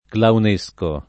vai all'elenco alfabetico delle voci ingrandisci il carattere 100% rimpicciolisci il carattere stampa invia tramite posta elettronica codividi su Facebook claunesco [ klaun %S ko ] (meglio che clownesco [id.]) agg.; pl. m. ‑schi